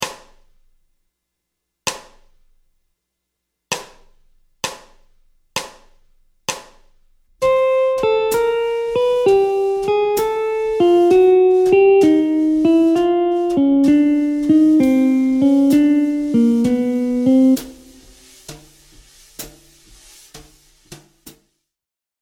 Gamme mineure mélodique ( I – mode mineur Majeur)
{1 2 b3 4 5 6 7∆}
Descente de gamme
Gamme-bop-desc-Pos-31-C-min-mel-1.mp3